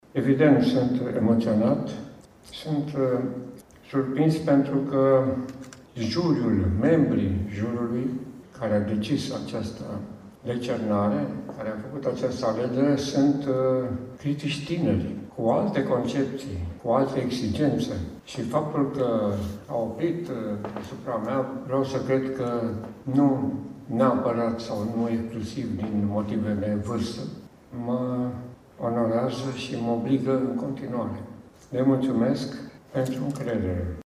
La Teatrul Mihai Eminescu din Botoșani a avut loc aseară Gala Culturii Naționale.
Premiul Național pentru excelență în cultură a fost decernat academicianului Mircea Martin: